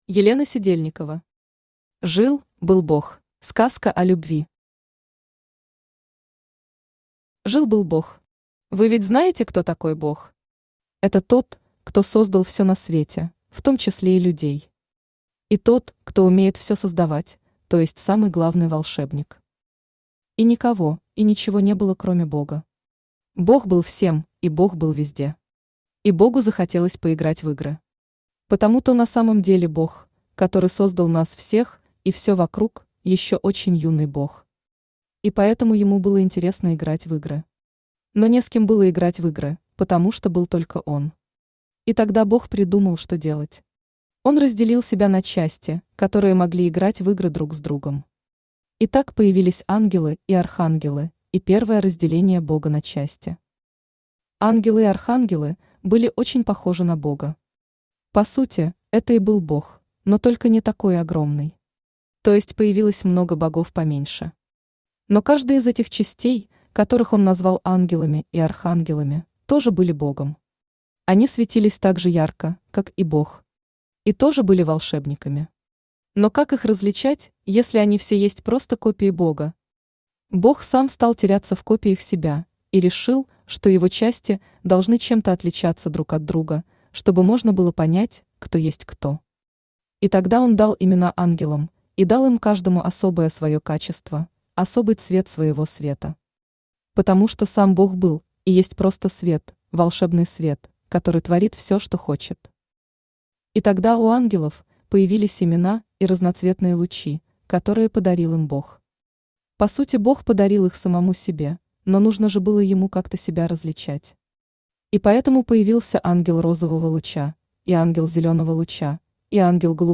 Аудиокнига Жил – был Бог | Библиотека аудиокниг
Aудиокнига Жил – был Бог Автор Елена Александровна Сидельникова Читает аудиокнигу Авточтец ЛитРес.